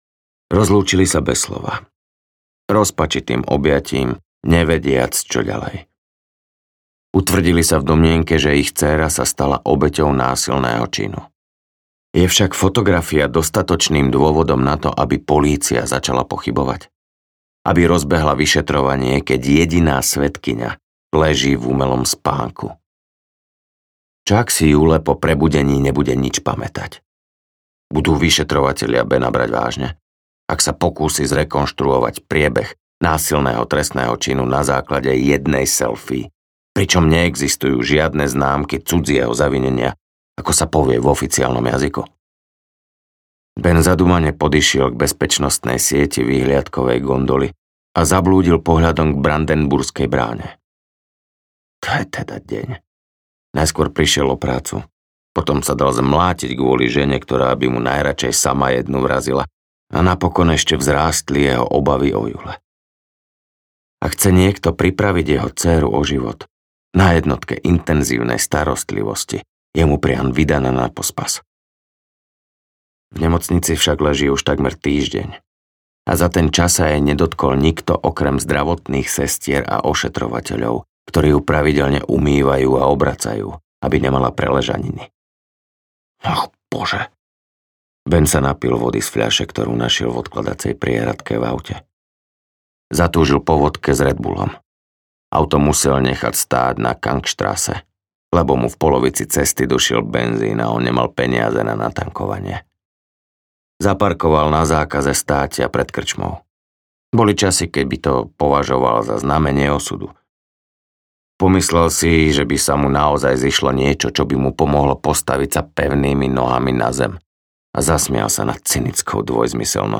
Osemnoc audiokniha
Ukázka z knihy